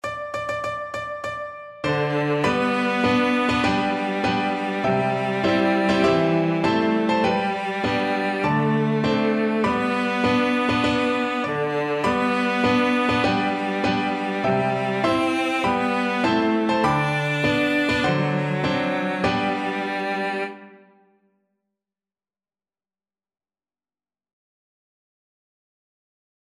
Traditional Trad. Ein Prosit Cello version
Cello
G major (Sounding Pitch) (View more G major Music for Cello )
With gusto!
4/4 (View more 4/4 Music)
Traditional (View more Traditional Cello Music)